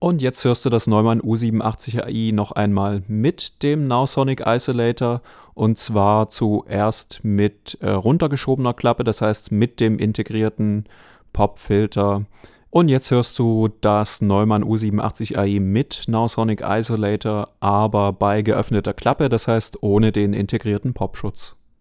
Zum Test platzierte ich die oben erwähnten Mikrofone in der Mitte eines akustisch unbehandelten Raums – rund 25 m² groß mit normaler Deckenhöhe, Laminat und normaler Möblierung.
Hier sind ein moderater Nachhall und leichte Flatterechos an der Stelle der Aufnahme zu hören.
Die Aufnahmen erfolgten selbstverständlich ohne Bearbeitung über ein hochwertiges XLR-Kabel direkt in einen der Mikrofonvorverstärker unseres bewährten Audio Interfaces RME Fireface 800.
Neumann U 87 Ai / 20 cm Entfernung
… mit Nowsonic Isolator:
Stets werden Raumhall und Nebengeräusche nennenswert abgeschwächt.
Gleichzeitig ist eine leichte Verstärkung der hohen Bässe zu spüren – als würde der Nahbesprechungseffekt verstärkt.
nowsonic_isolator_test__neumann_u_87_ai__20cm__mit.mp3